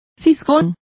Complete with pronunciation of the translation of snoop.